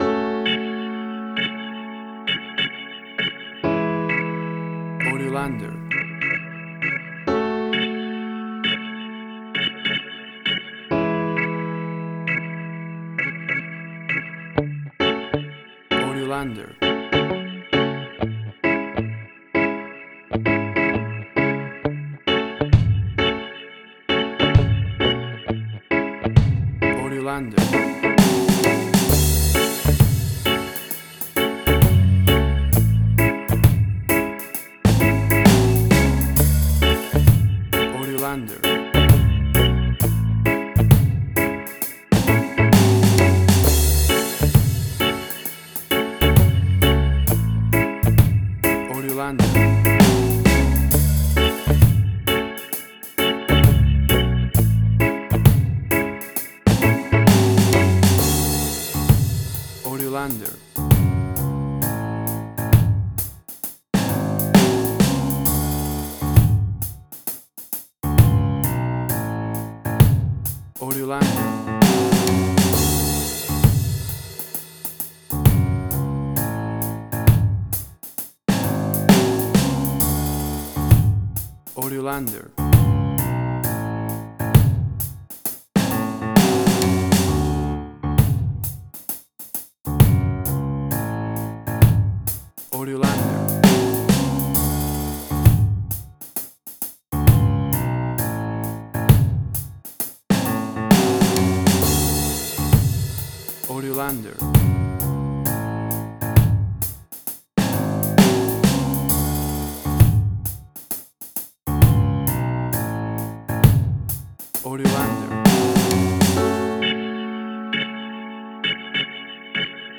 A chilled and relaxed piece of smooth reggae music!
WAV Sample Rate: 16-Bit stereo, 44.1 kHz
Tempo (BPM): 66